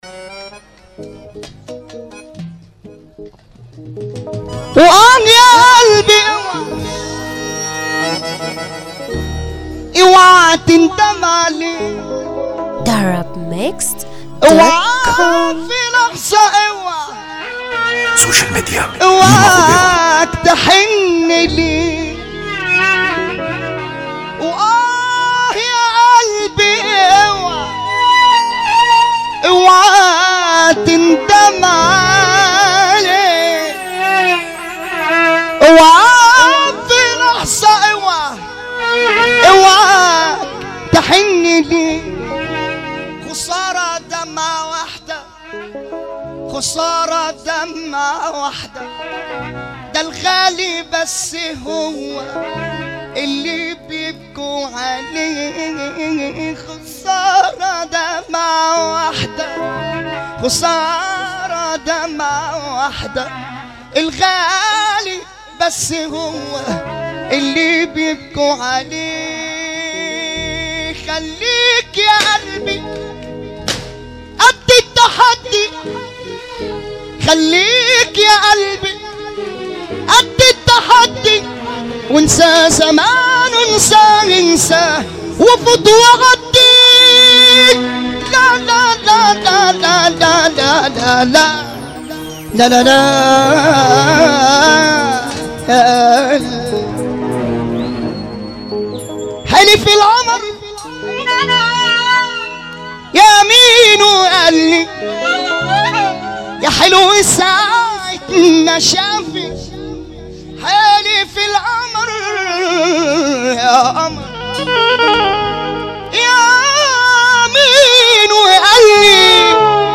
موال
حزين موت